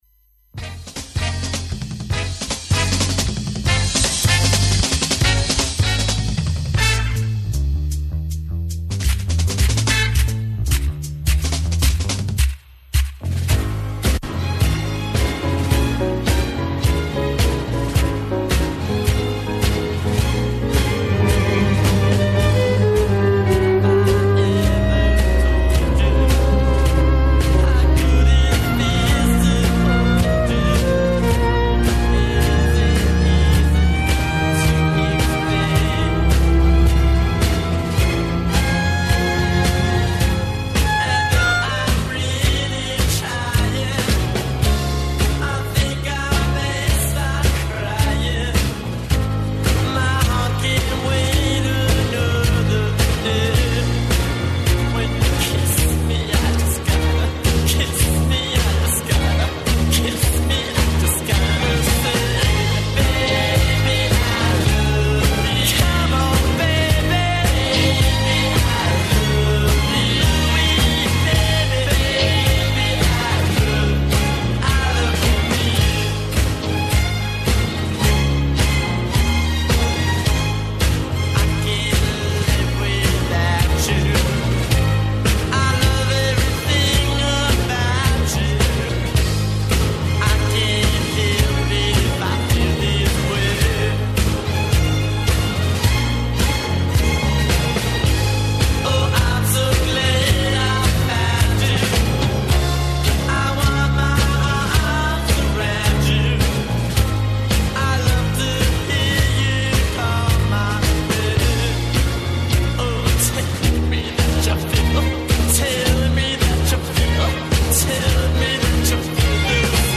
Уживо из Сремске Митровице. Представљамо вам културни и музички живот овог града.